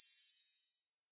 snd_fairyshoot.ogg